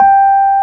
Index of /90_sSampleCDs/Roland LCDP10 Keys of the 60s and 70s 2/PNO_Rhodes/PNO_73 Suitcase
PNO G4 P  0D.wav